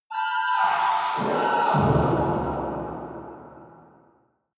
Royalty free sounds: Impacts